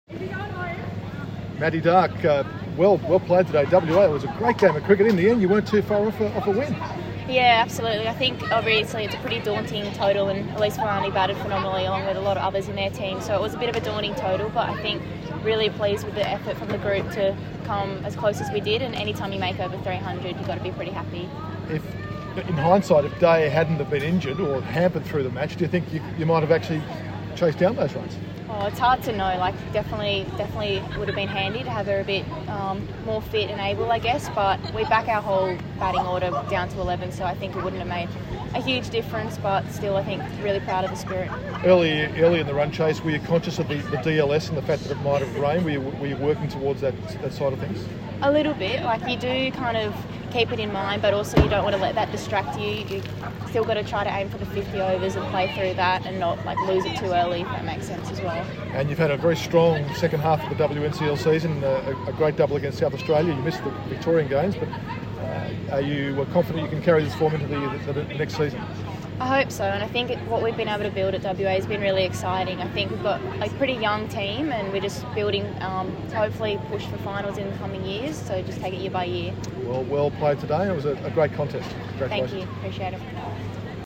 speaking after today’s match at Blundstone Arena